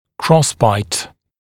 [‘krɔsbaɪt][‘кросбайт]перекрестный прикус, перекрестное перекрытие